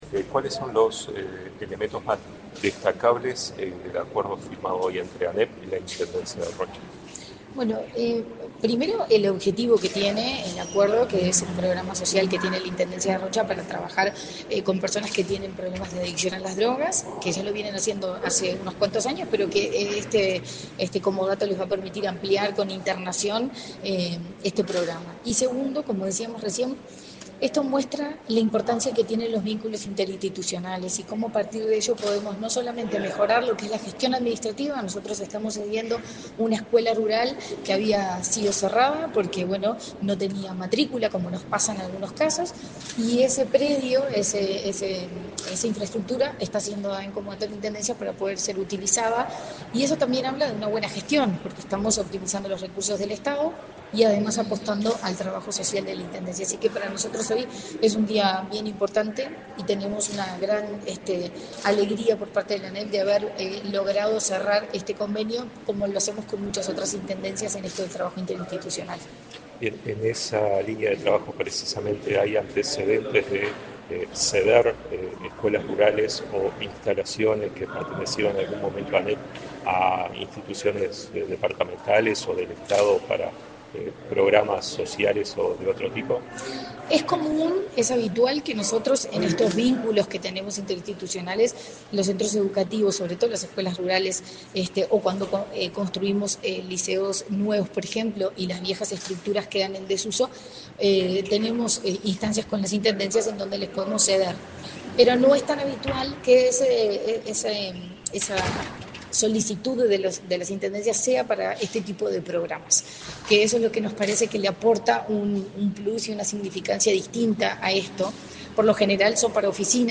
Entrevista a la presidenta de ANEP, Virginia Cáceres